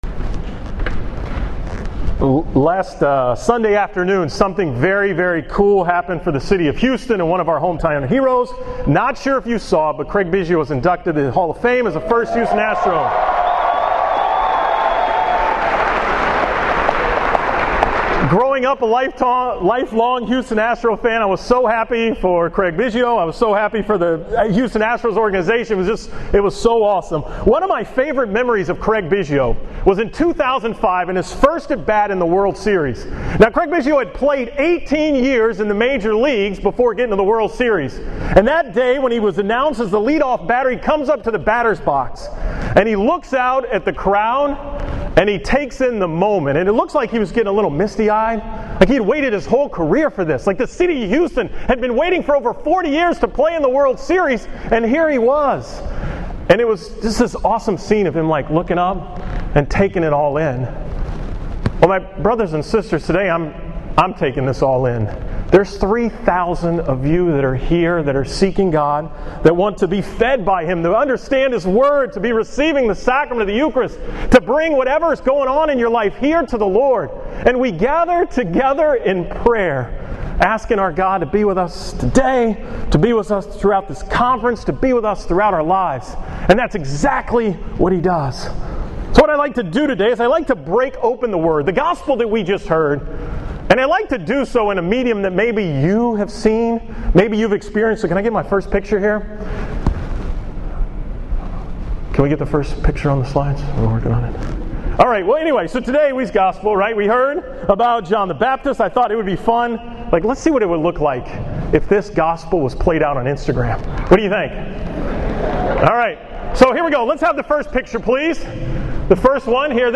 From the Archdiocesan Youth Conference on Saturday, August 1, 2015
Here were the slides that went with the homily: AYC 2015